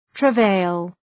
Προφορά
{trə’veıl}